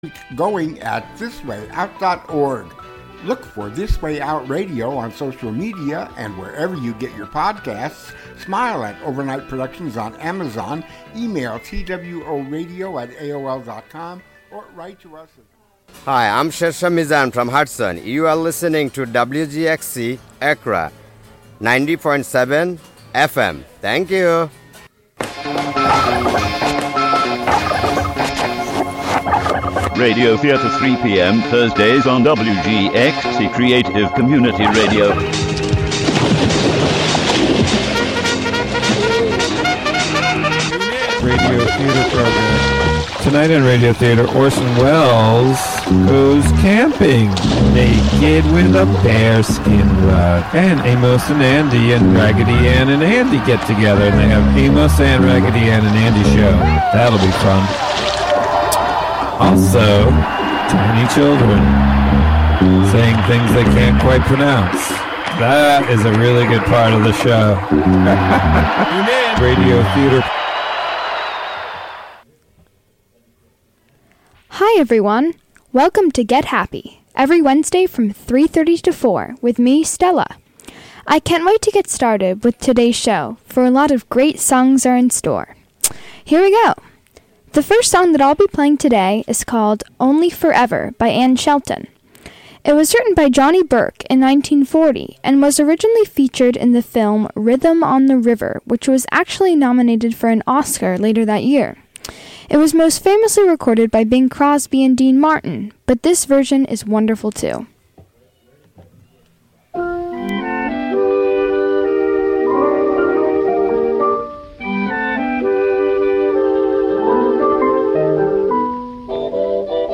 1930s and 1940s music, with an occasional foray into other genres